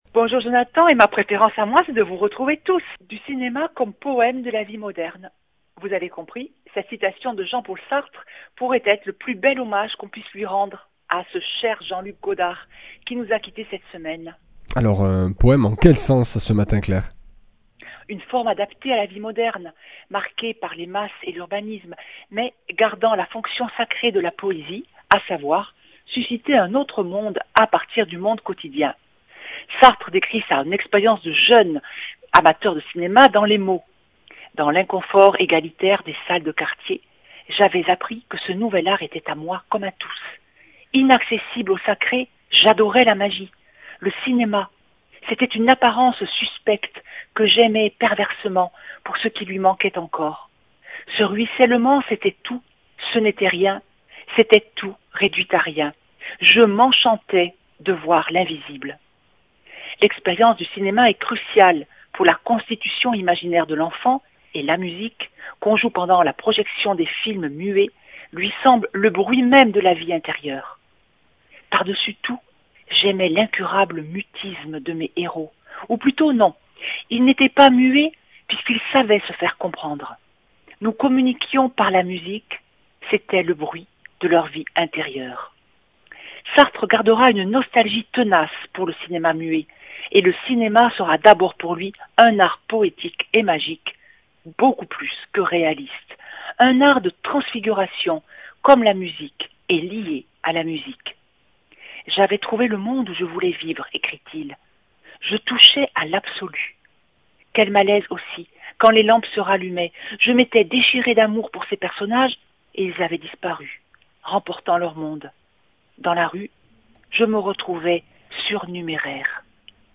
Chroniqueuse